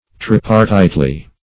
tripartitely - definition of tripartitely - synonyms, pronunciation, spelling from Free Dictionary Search Result for " tripartitely" : The Collaborative International Dictionary of English v.0.48: Tripartitely \Trip`ar*tite*ly\, adv. In a tripartite manner.